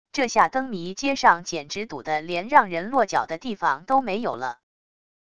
这下灯谜街上简直堵得连让人落脚的地方都没有了wav音频生成系统WAV Audio Player